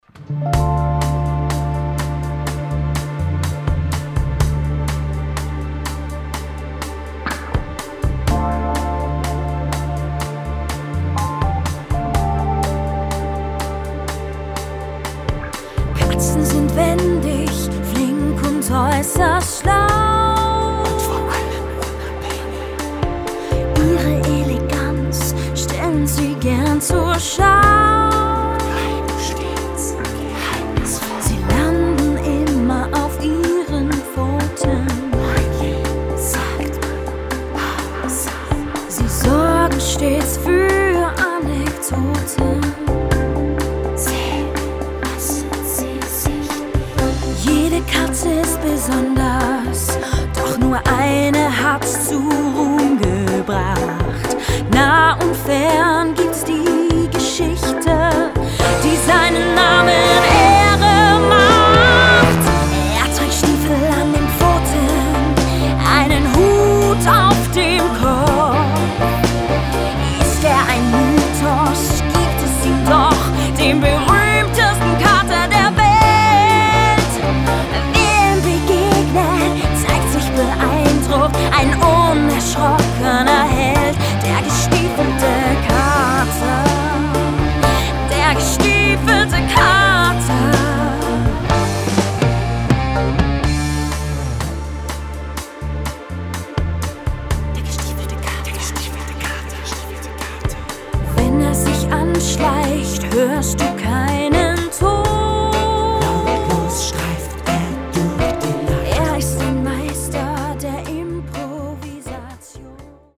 man erlebte ein Kindermusical das einmal mehr bewies